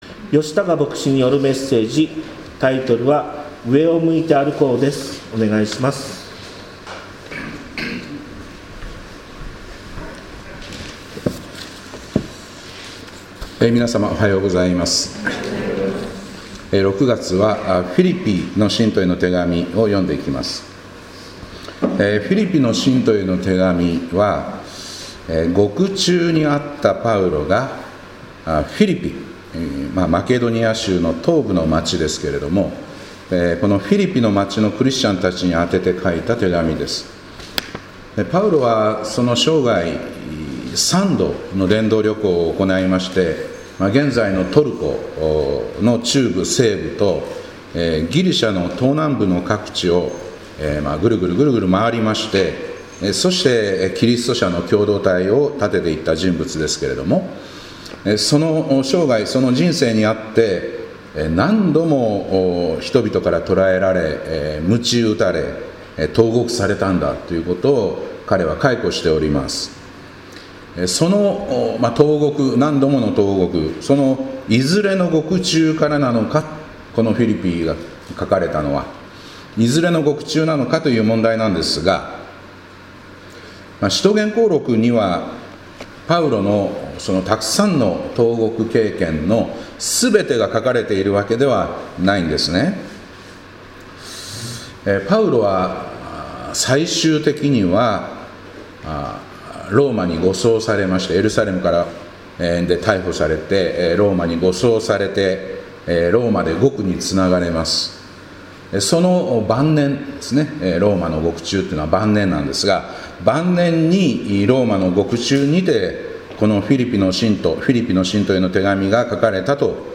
2025年6月1日礼拝「上を向いて歩こう」